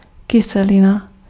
Ääntäminen
Synonyymit aigre (slangi) LSD Ääntäminen France: IPA: /a.sid/ Haettu sana löytyi näillä lähdekielillä: ranska Käännös Konteksti Ääninäyte Substantiivit 1. kyselina {f} kemia Adjektiivit 2. kyselý {m} Suku: m .